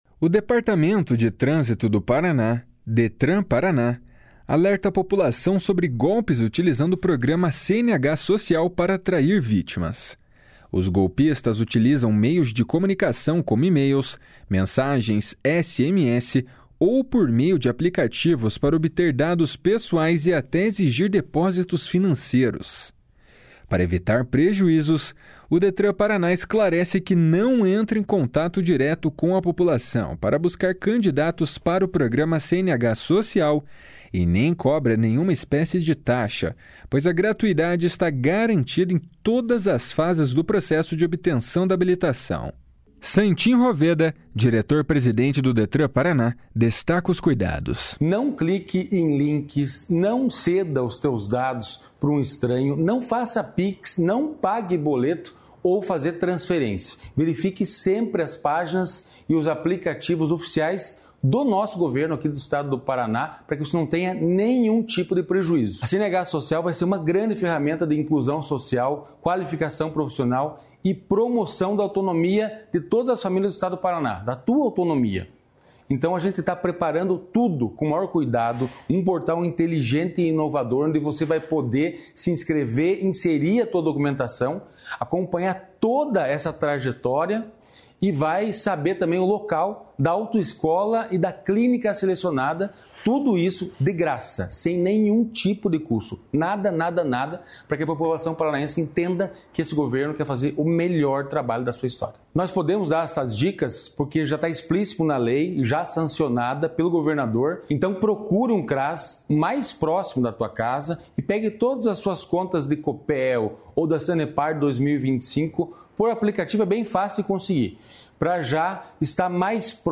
Santin Roveda, diretor-presidente do Detran-PR, destaca os cuidados. // SONORA SANTIN ROVEDA //